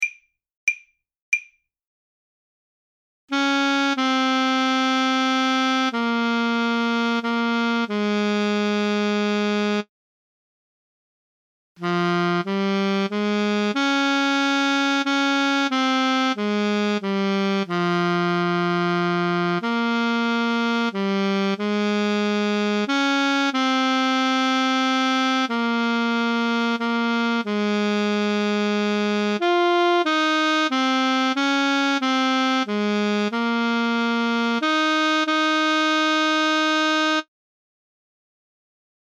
voix 3 seule